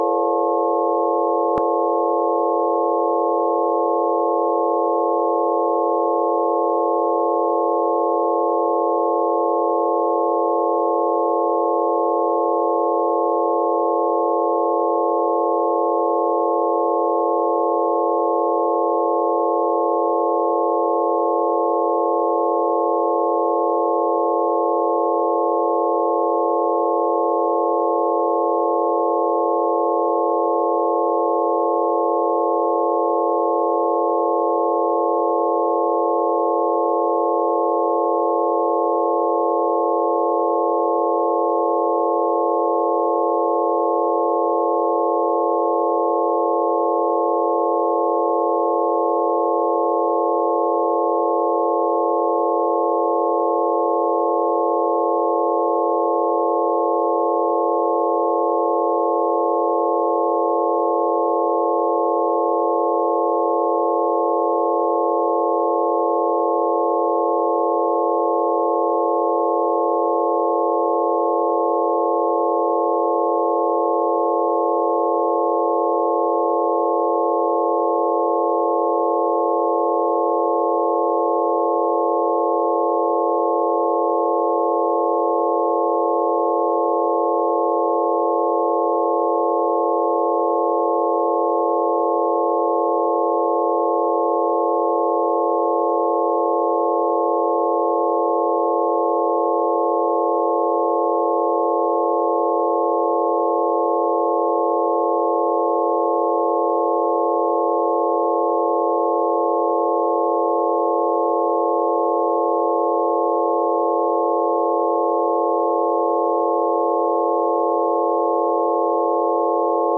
基于我发现的研究 我做了一些超声处理（将数据缩放到可听范围）以“可视化”听起来如何。 注意：这是测量数据的超声处理，而不是实际的顺势疗法补救措施。